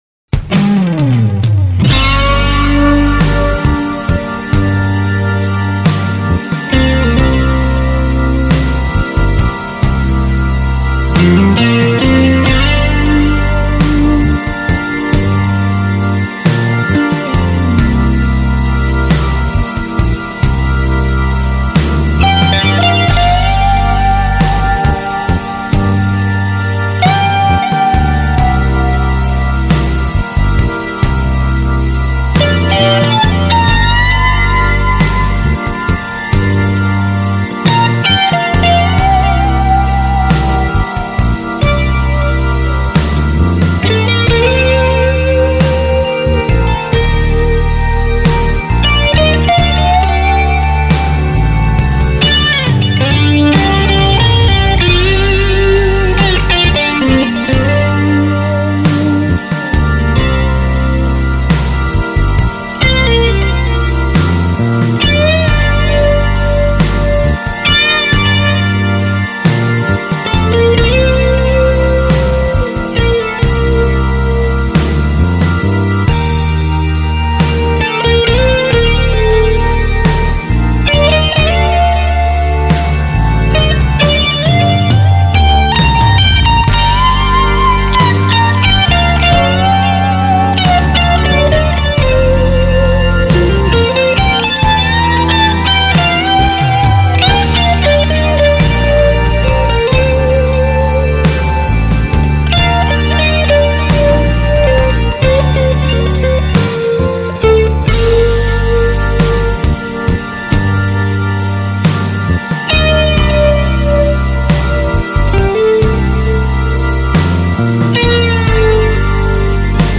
Tachchen, hier ist meine Version zum 71er: wish I were pink - mp3 Ist ne Aufnahme, die ich mit dem Womanizer gemacht habe.